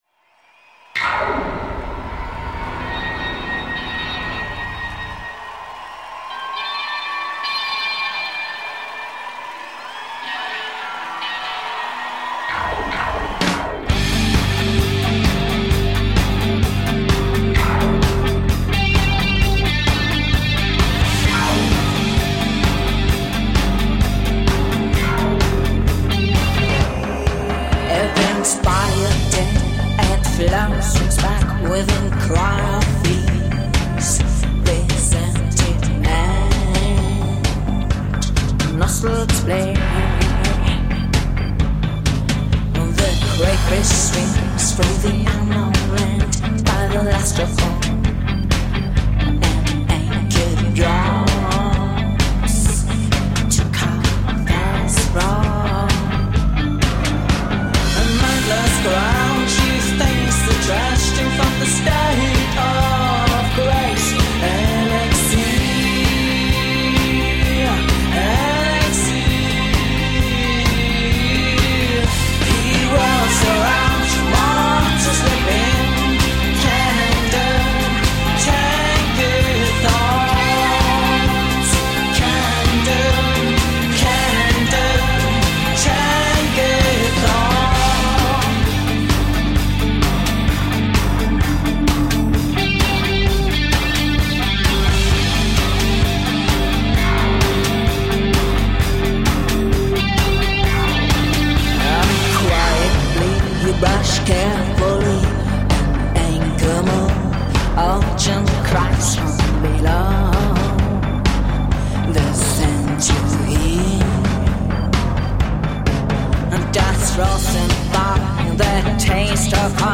1980's style new wave rock.